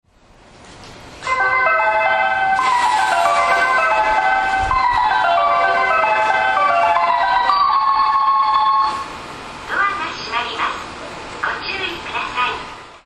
メロディーは一般的です。2005年12月に自動放送を変更しております。